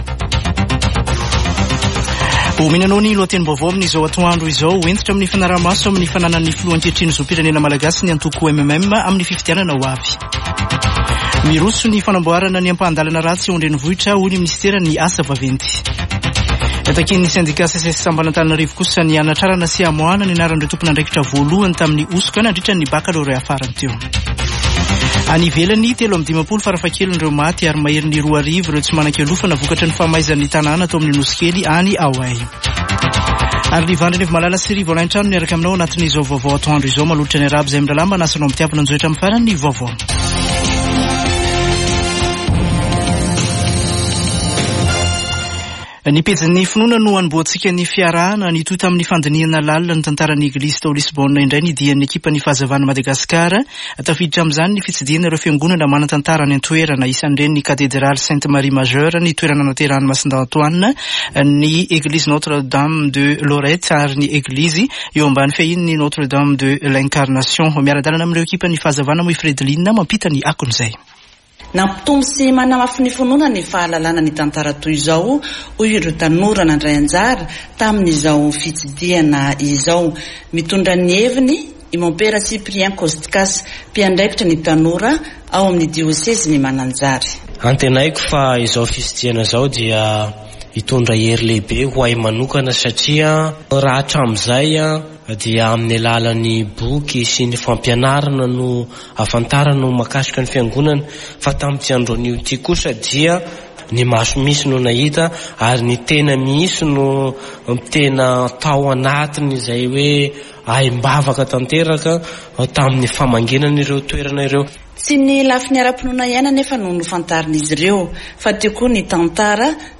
[Vaovao antoandro] Zoma 11 aogositra 2023